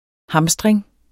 Udtale [ ˈhɑmsdʁeŋ ]